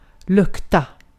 Ääntäminen
IPA : /smɛl/